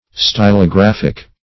stylographic - definition of stylographic - synonyms, pronunciation, spelling from Free Dictionary
Stylographic \Sty`lo*graph"ic\, a.
stylographic.mp3